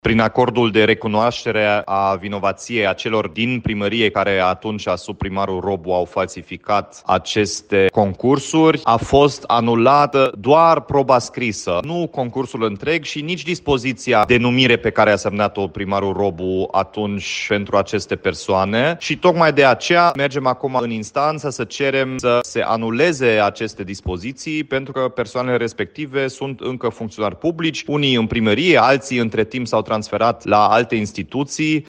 Două salariate ale municipalității au recunoscut că aceste concursuri au fost trucate, iar lucrările candidaților au fost anulate. În cazul angajărilor, însă, este nevoie de acțiuni în instanță, spune primarul Dominic Fritz.